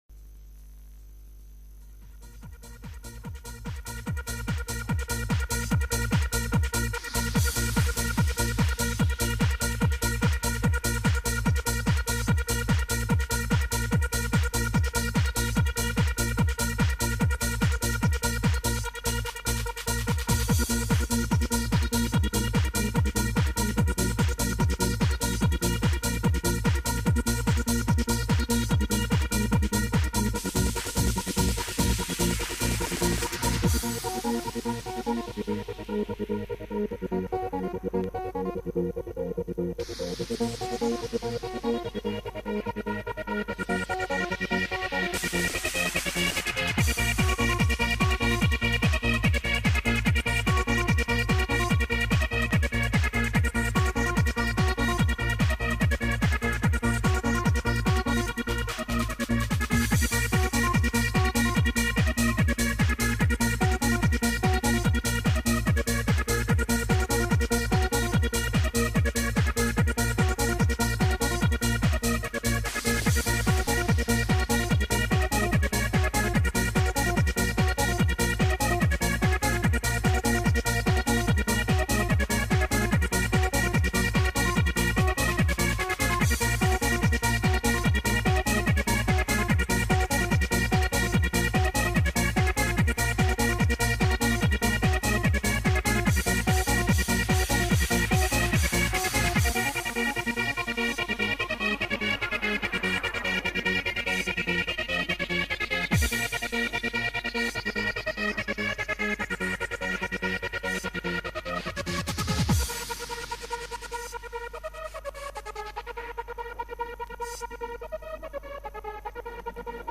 Vocals Trance Happy Hardcore